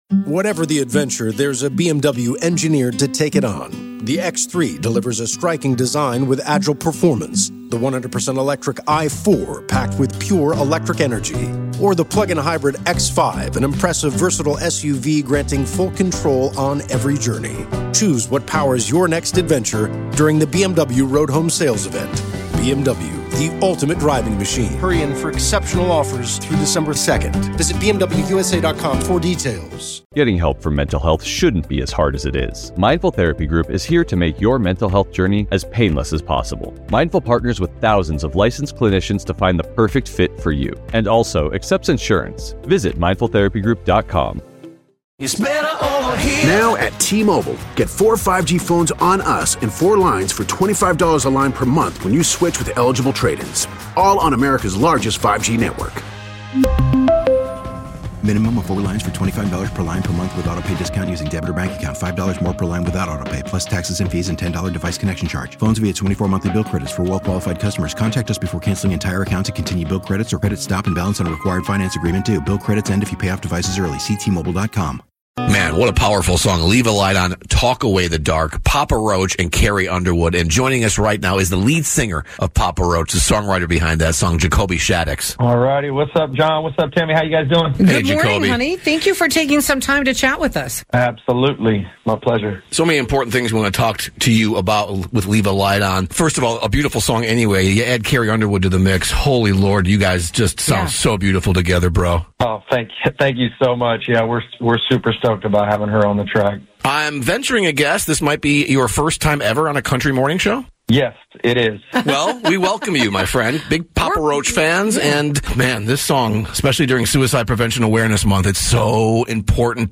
We talked to lead singer Jacoby Shaddix this morning about the song and how intimidating it is to sing alongside Carrie Underwood.